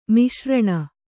♪ miśraṇa